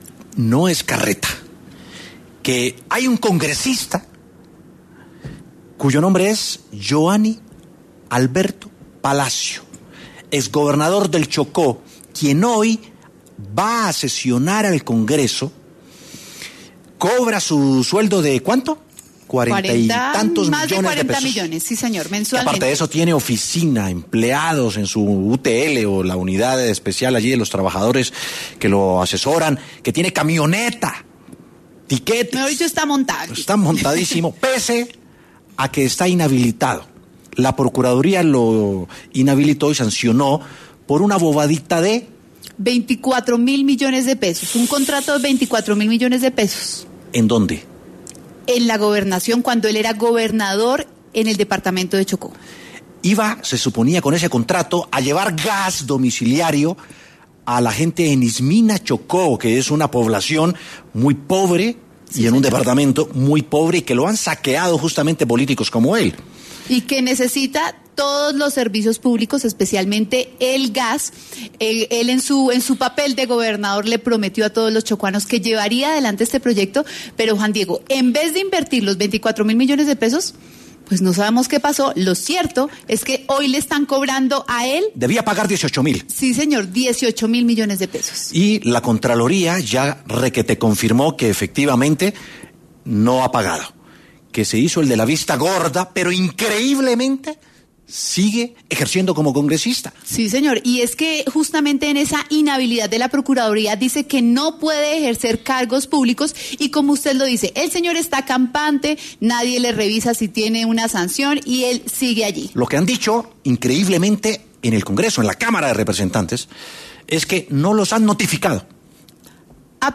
W Sin Carreta llamó a la oficina del congresista inhabilitado: contestaron y esto dijeron
Tras esto, se cortó la llamada con la oficia del congresista.